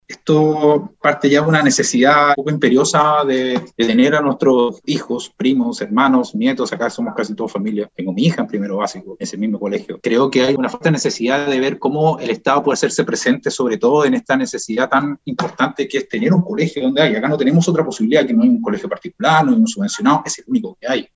Por su parte, el alcalde de Juan Fernández, Pablo Manríquez, comentó que se encuentran trabajando en paralelo en una propuesta de diseño sobre el terreno.
alcalde-colegio-juan-fernandez.mp3